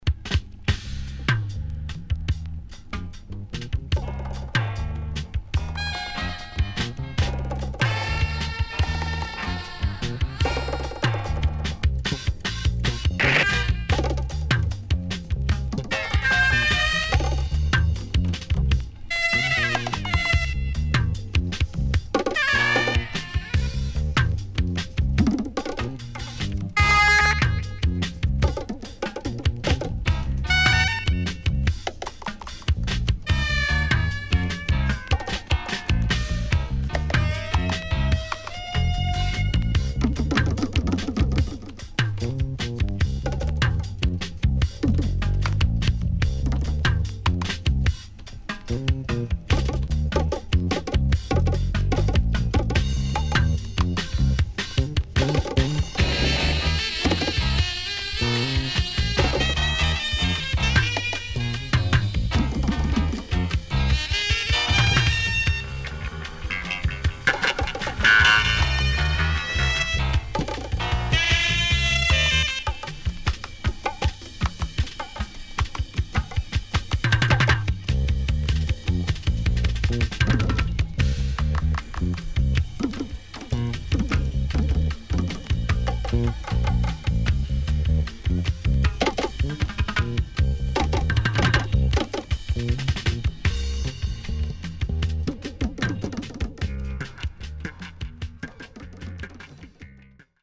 SIDE A:少しチリノイズ入りますが良好です。